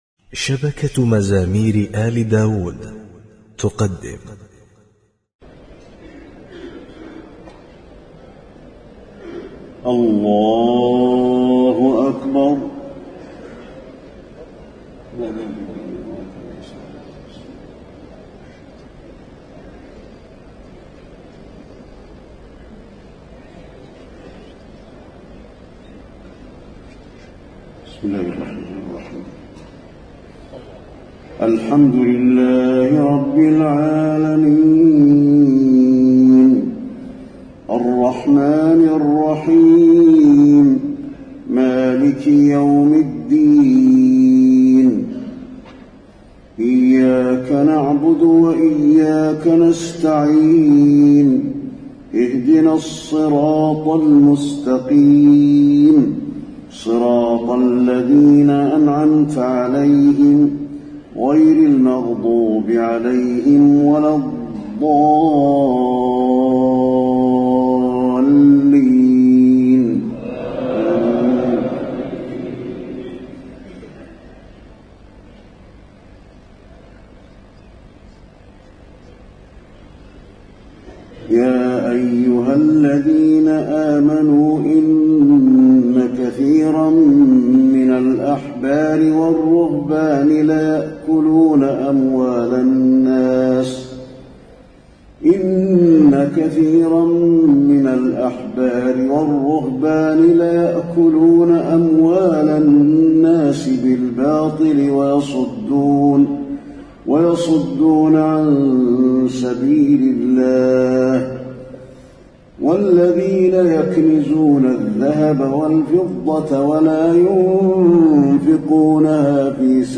تهجد ليلة 28 رمضان 1432هـ من سورة التوبة (34-99) Tahajjud 28 st night Ramadan 1432H from Surah At-Tawba > تراويح الحرم النبوي عام 1432 🕌 > التراويح - تلاوات الحرمين